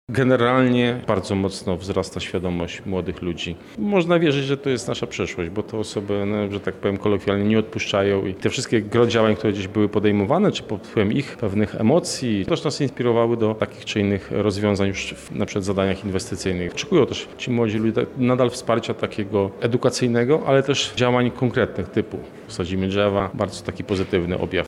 Dzisiaj (13.12) odbyło się spotkanie podsumowujące działania proekologiczne realizowane w 2023 roku w ramach Europejskiej Stolicy Młodzieży.
Artur Szymczyk– mówi Artur Szymczyk, Zastępca Prezydenta Lublina do spraw Inwestycji i Rozwoju.